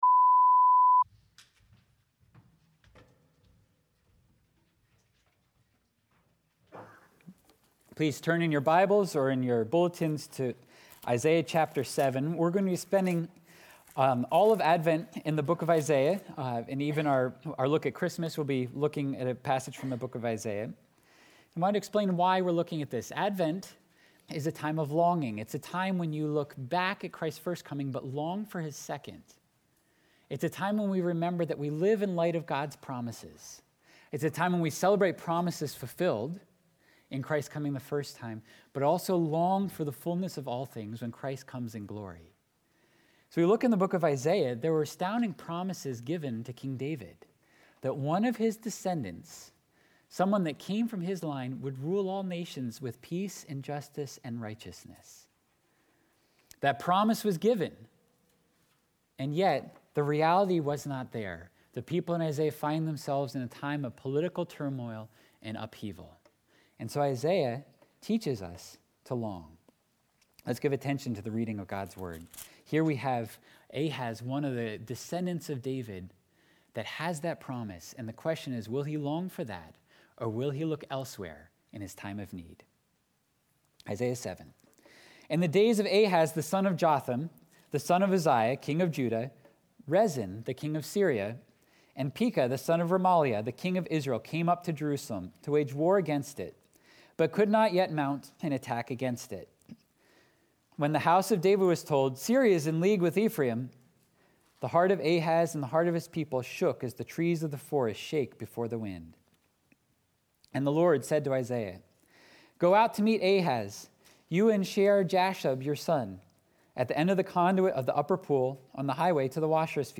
12.5.21-sermon-audio.mp3